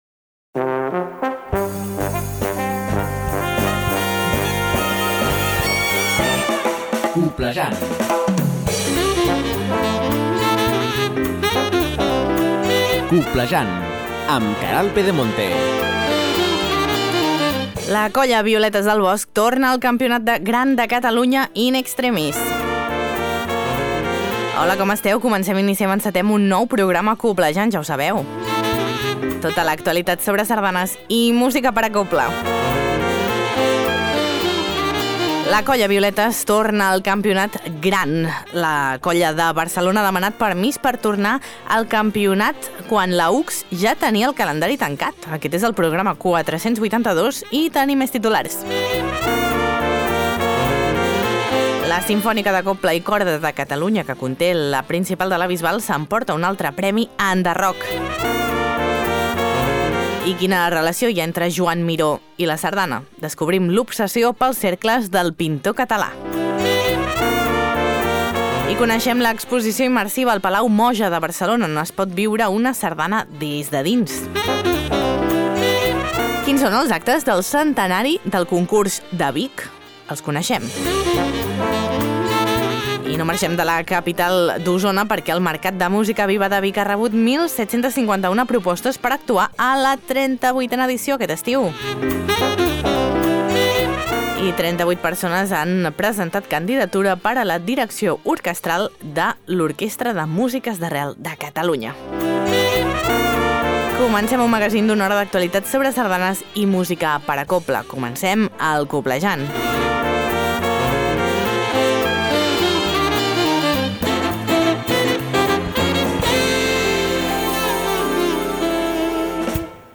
Tot això i molt més a Coblejant, un magazín de Ràdio Calella Televisió amb l’Agrupació Sardanista de Calella per a les emissores de ràdio que el vulguin i s’emet arreu dels Països Catalans. T’informa de tot allò que és notícia al món immens de la sardana i la cobla.